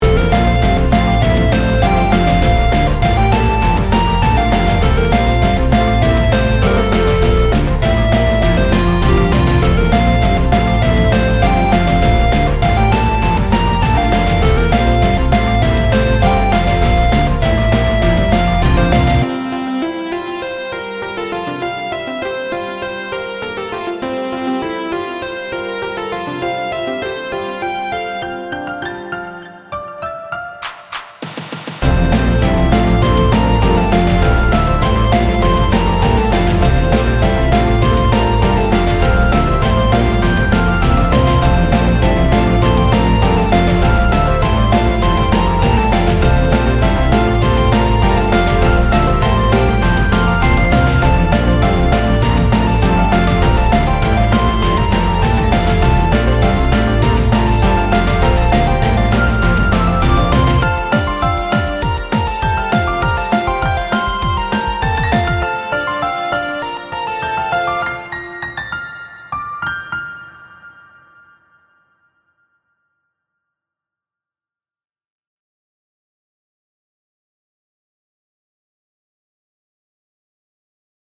pop boss battle